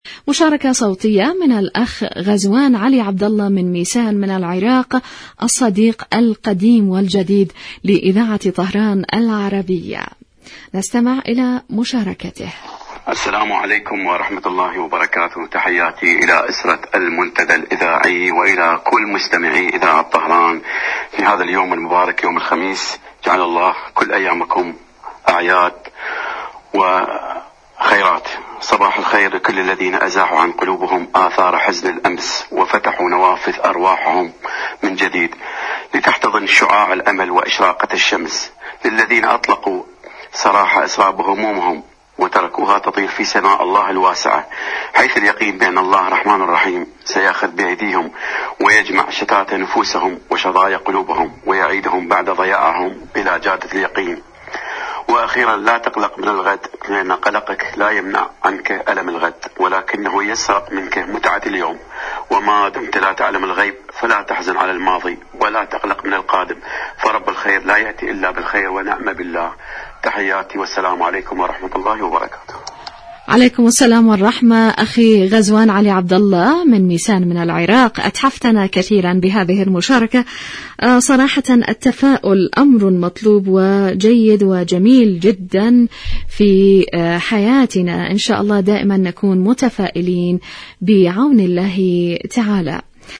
إذاعة طهران-المنتدى الإذاعي